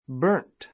burnt bə́ː r nt バ ～ン ト 動詞 burn の過去形・過去分詞 形容詞 焼けた, 焦 こ げた; やけどをした A burnt child dreads the fire.